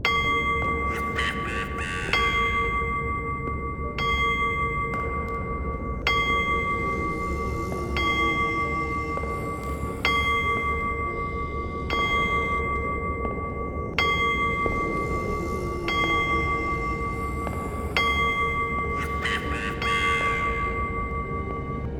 cuckoo-clock-11.wav